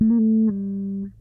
bass8.wav